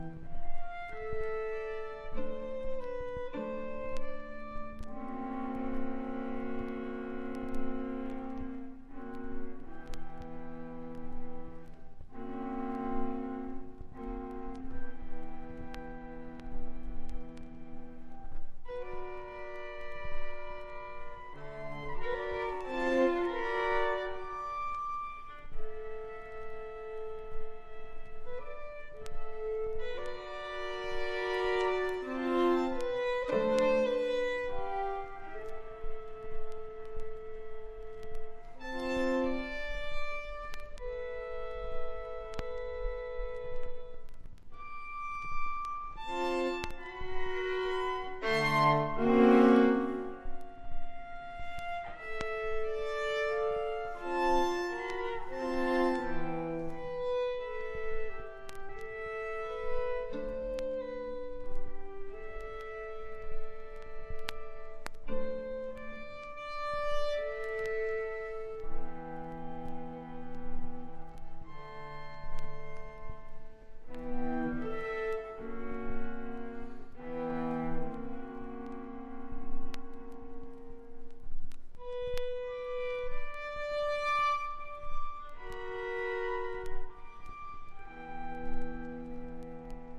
現代音楽